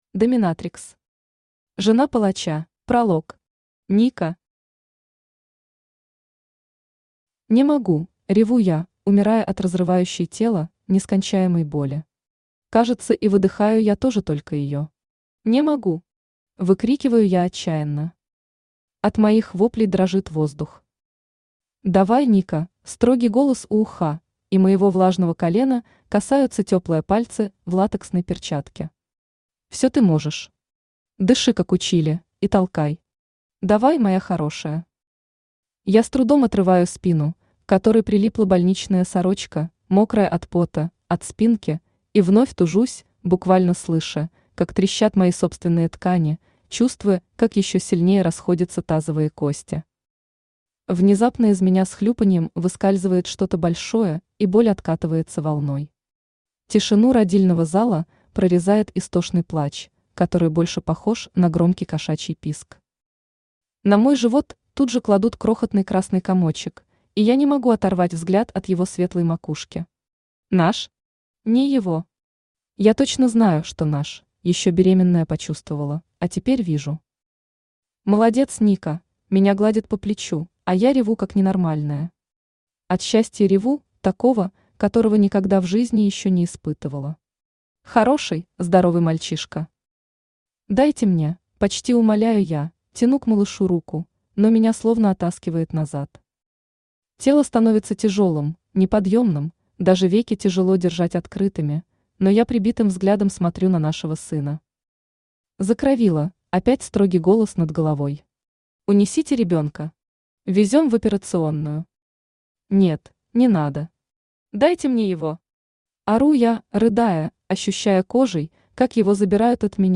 Аудиокнига Жена Палача | Библиотека аудиокниг
Aудиокнига Жена Палача Автор ДОМИНАТРИКС Читает аудиокнигу Авточтец ЛитРес.